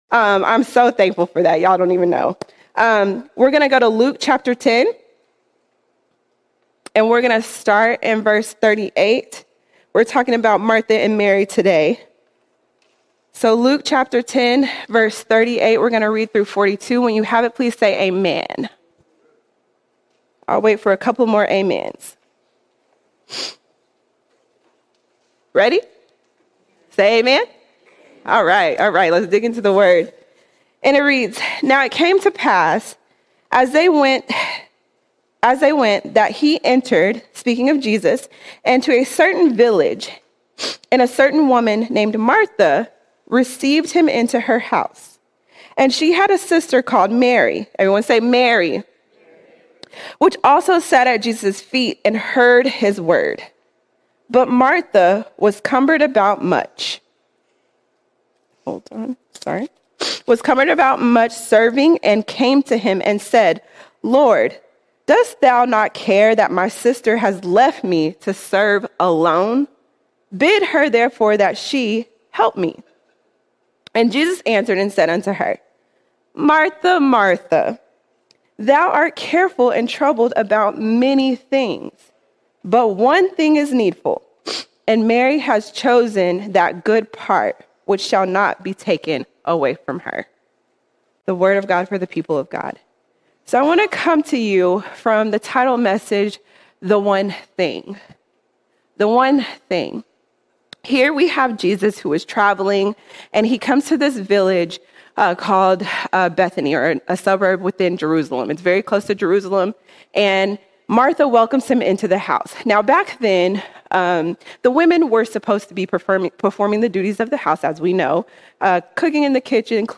8 September 2025 Series: Sunday Sermons All Sermons The One Thing The One Thing Mary chose the one thing, time at Jesus’ feet.